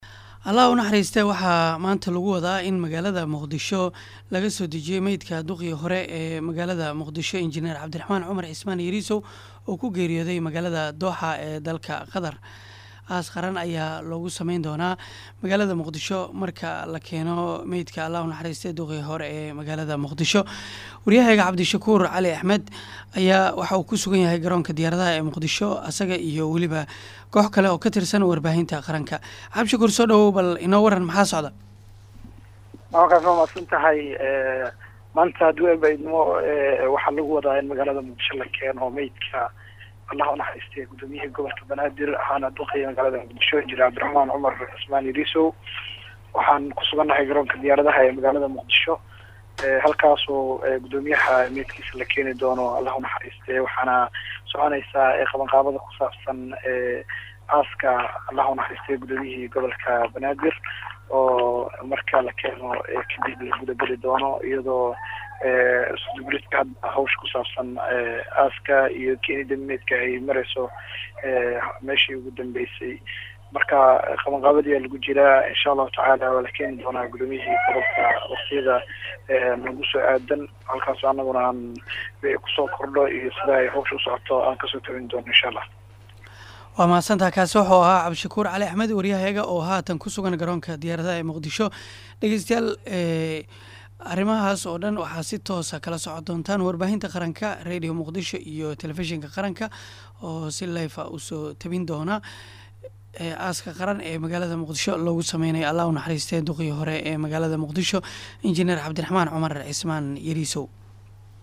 oo ku sugan Garoonka Aadan Cadde